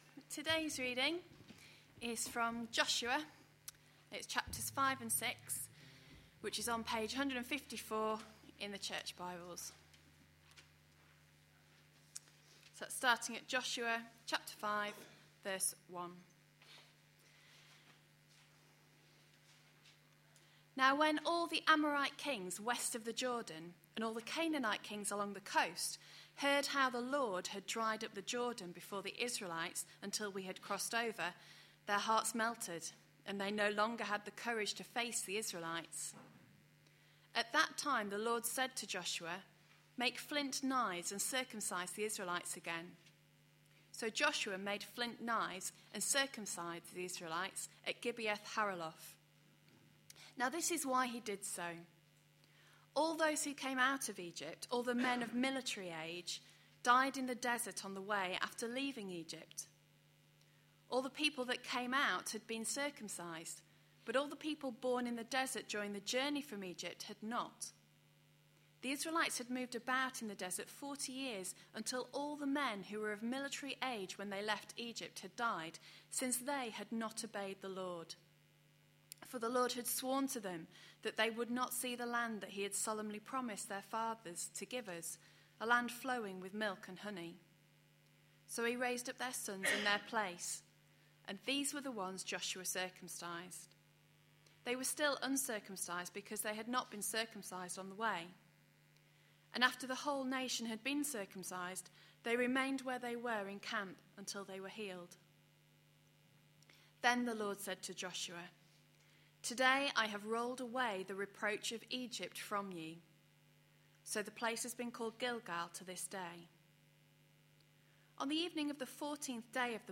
A sermon preached on 29th April, 2012, as part of our Entering God's Rest series.